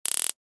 Taser Shock 3
yt_z5_KueGdvrk_taser_shock_3.mp3